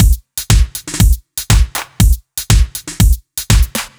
Index of /musicradar/french-house-chillout-samples/120bpm/Beats